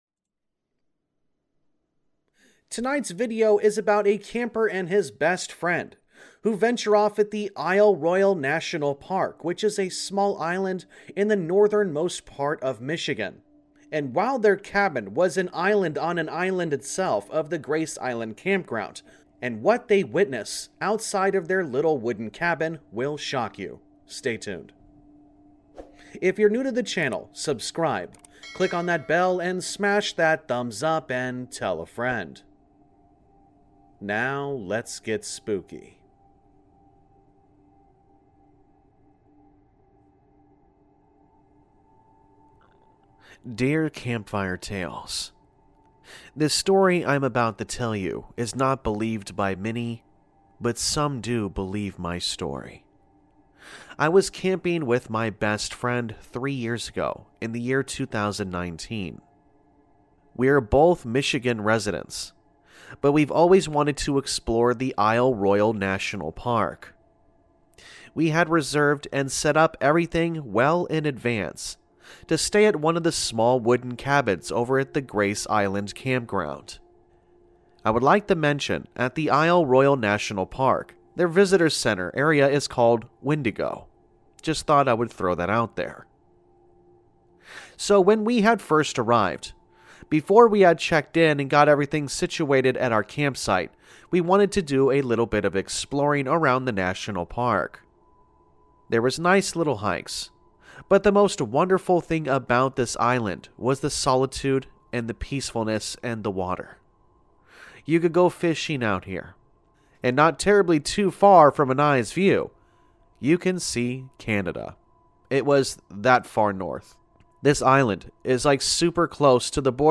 All Stories are read with full permission from the authors: Story Credit - anonymous